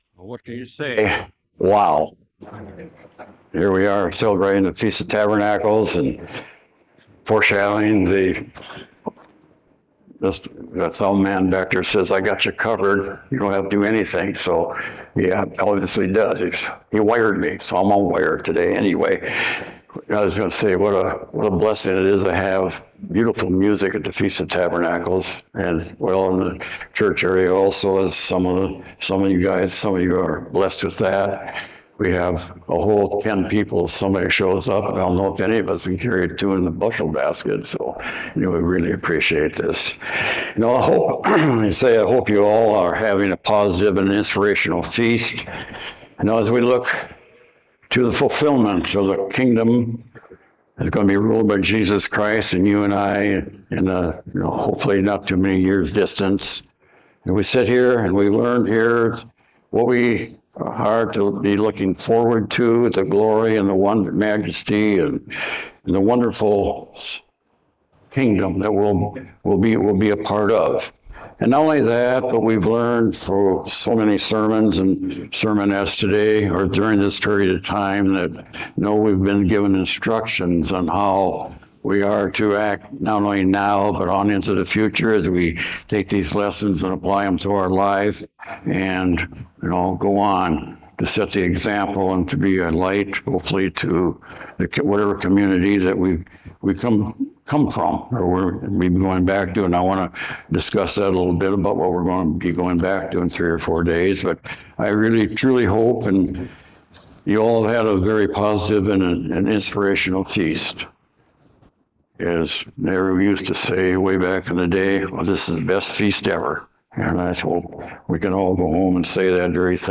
This sermon reminds us that while anxiety can take hold of our lives, God’s Word consistently calls us to trust Him and not be consumed by worry about the future. It emphasizes that by turning to our Heavenly Father in prayer and deepening our relationship with Him, we can find true peace and understanding.